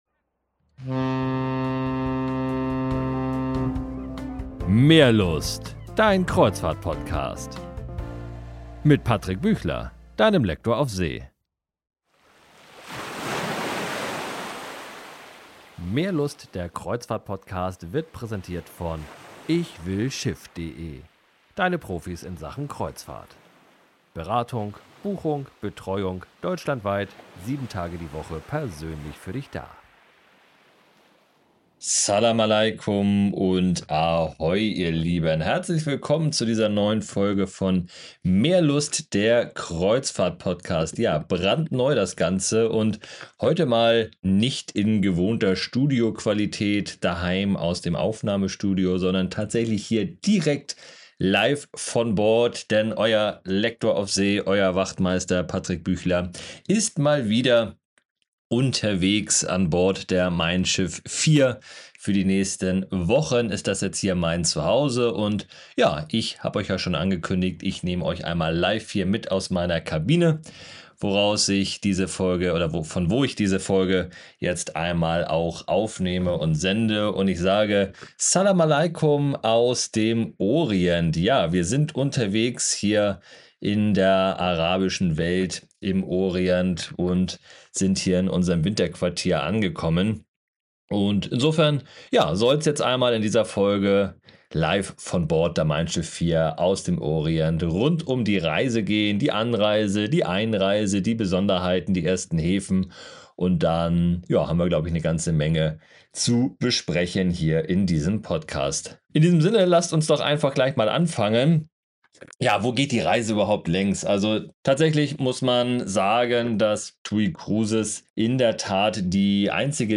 #37 Live von Bord Orient & Dubai ~ Meerlust - Der Kreuzfahrt Podcast